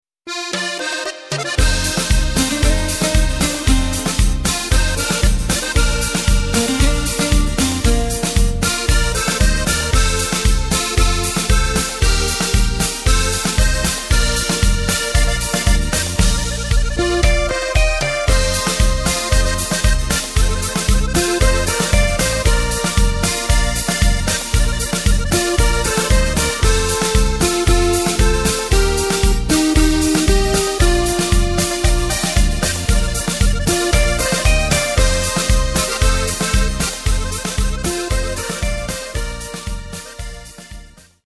Takt:          4/4
Tempo:         115.00
Tonart:            Bb
Coverversion (Schlager) aus dem Jahr 2021!